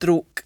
[drrOHwk]